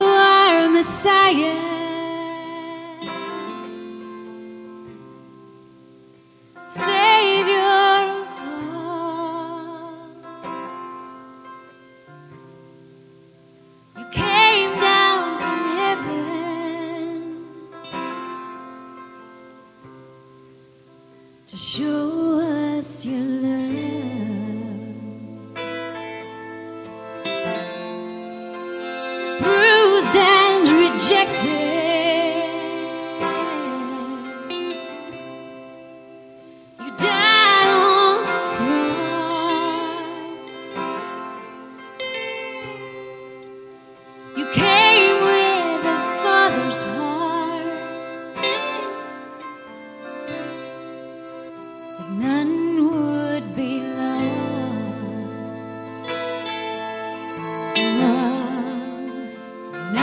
zeitgemäße, gemeindetaugliche Lobpreismusik
• Sachgebiet: Praise & Worship